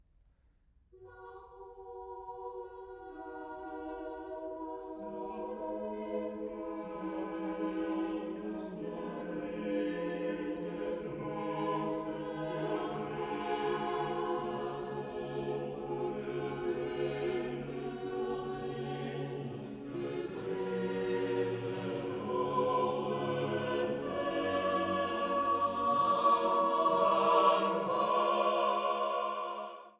Komposition für gemischten Chor